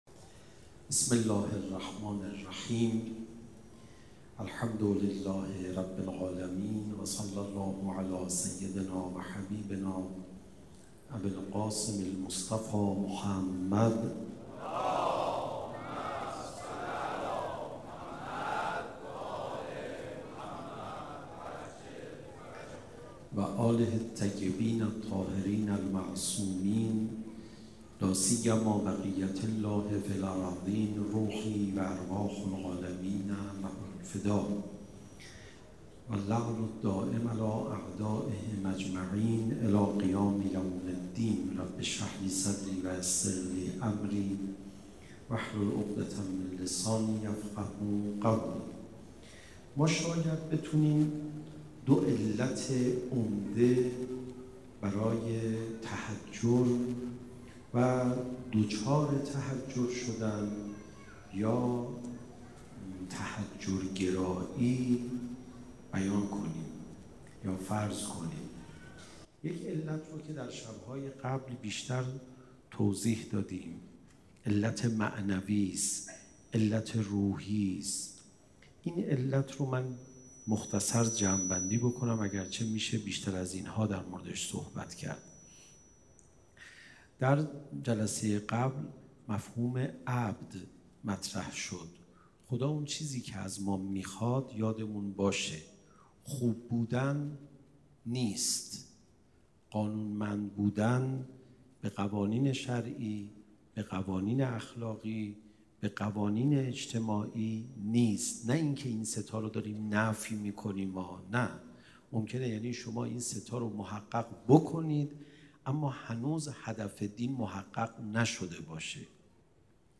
سخنرانی: سخنرانی حجت‌الاسلام والمسلمین علیرضا پناهیان